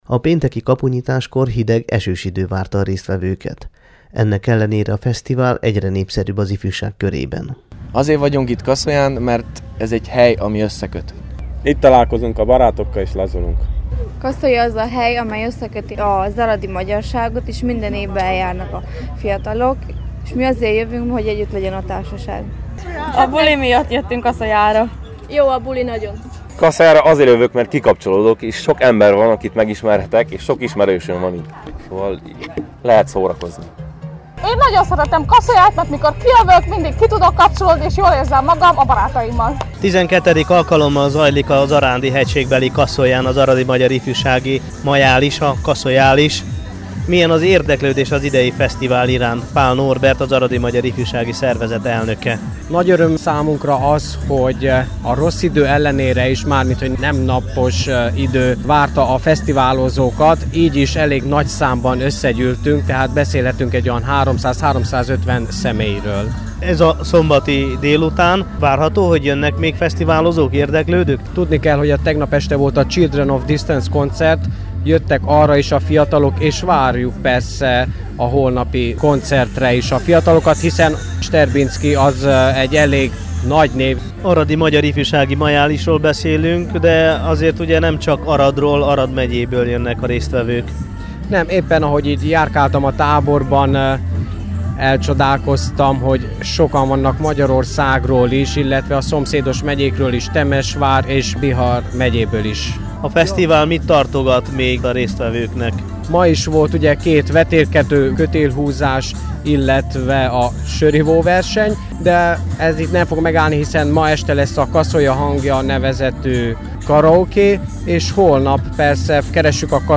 Tekintse meg képeinket és hallgassa meg a Temesvári Rádió ifjúsági műsora számára készült összeállítást: